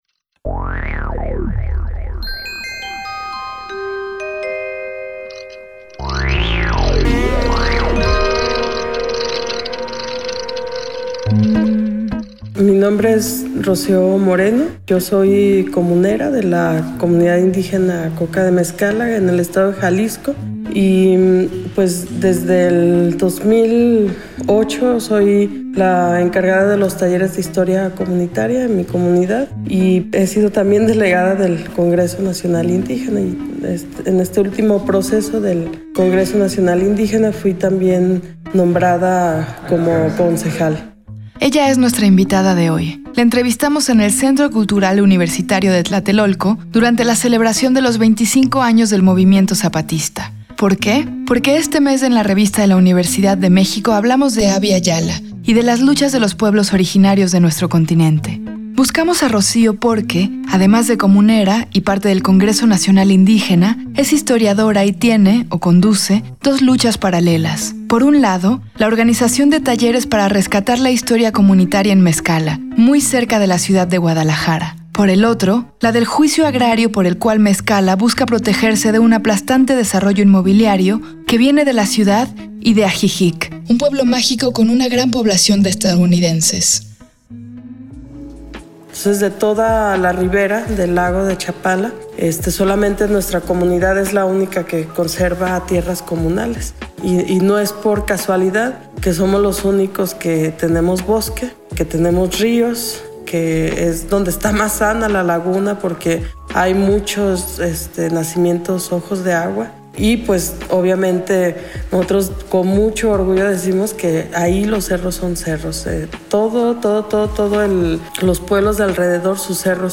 Fue transmitido el jueves 18 de abril de 2019 por el 96.1 FM.